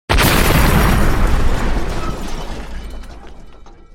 explode.ogg